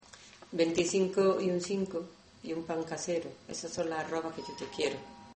Materia / geográfico / evento: Canciones de mecedor Icono con lupa
Alhama de Granada Icono con lupa
Secciones - Biblioteca de Voces - Cultura oral